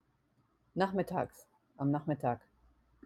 nachmittags / am Nachmittag |(ca. 13-17 Uhr) (NACH-mit-tags)